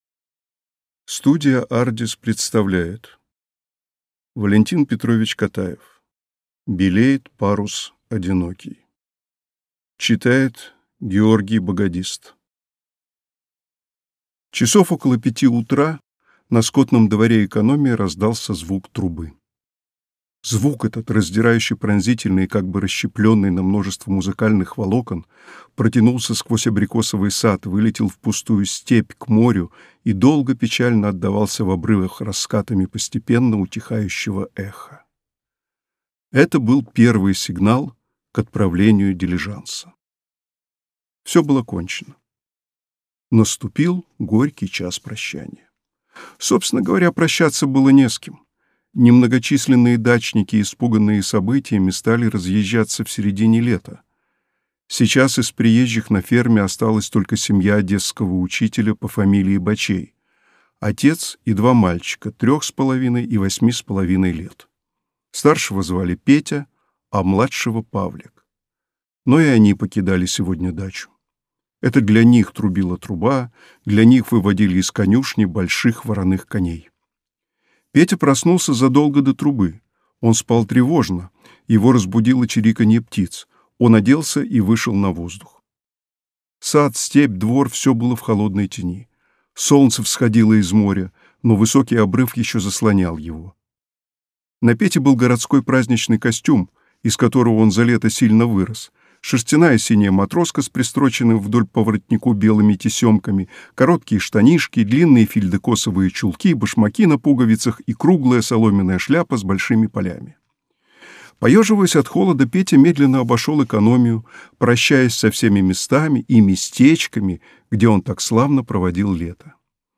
Аудиокнига Белеет парус одинокий | Библиотека аудиокниг